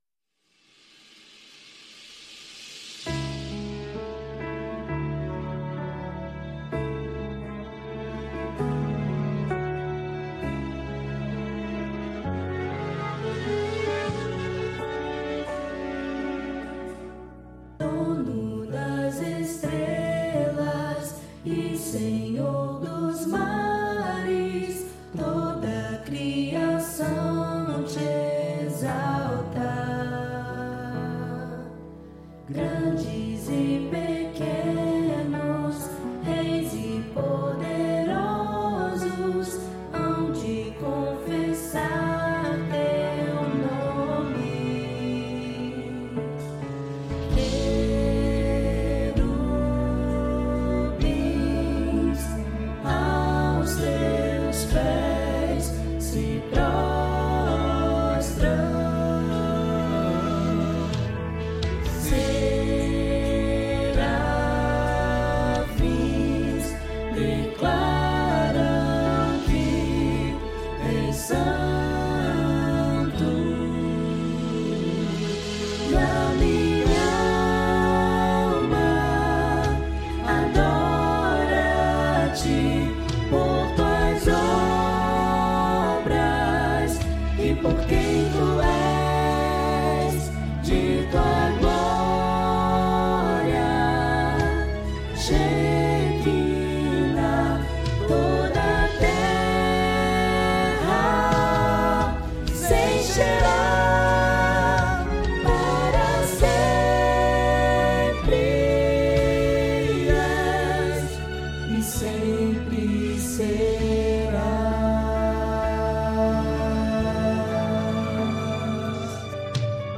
Arranjo Coral (SCTB)
Tonalidade: Lá maior